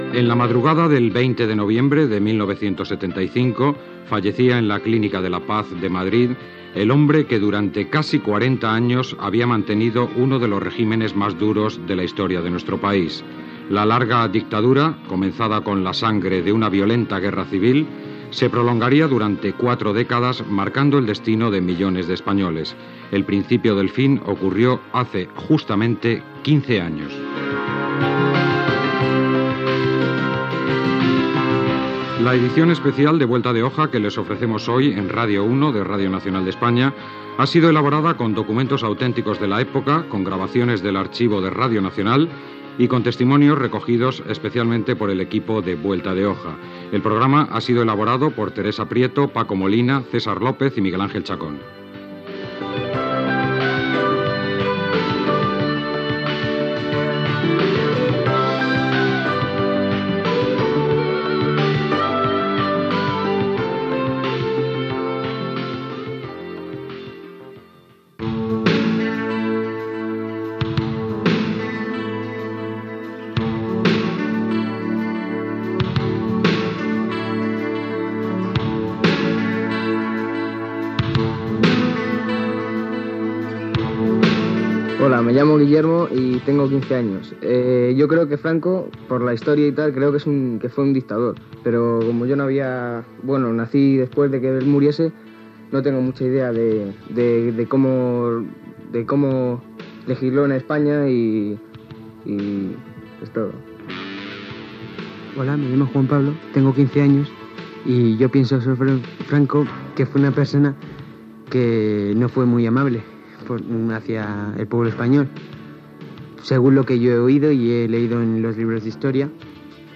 Presentació, equip del programa. Espai dedicat als 15 anys de la mort del cap d'estat Francisco Franco, amb testimonis diversos de nois espanyols de 15 anys
Informatiu